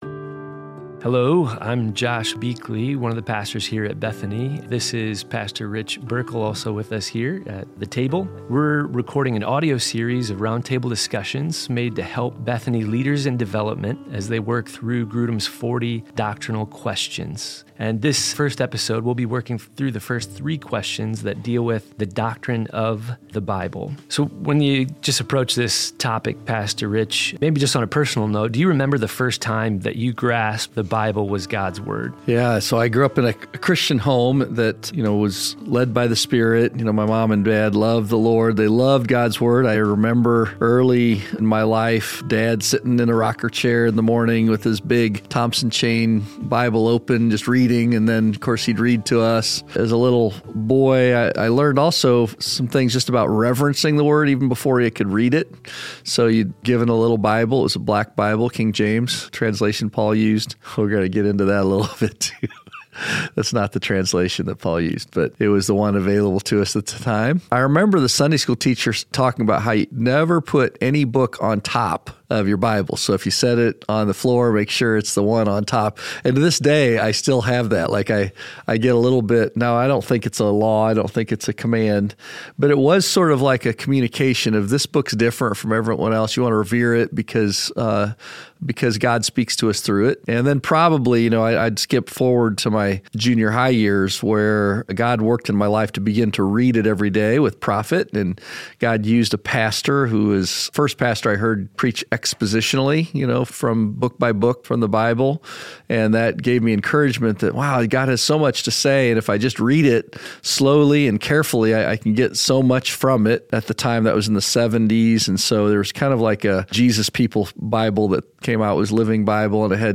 Theme: Why the Bible is Foundational for Faith and Leadership Personal Beginnings with Scripture Both pastors shared their early encounters with the Bible—R ...